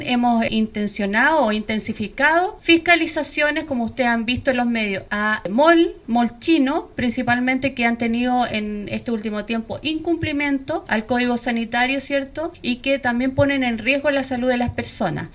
Por su parte, la seremi de Salud, Karin Solís, indicó que verificarán el cumplimiento de la normativa en centros comerciales chinos y realizarán fiscalización de alimentos en distintos locales.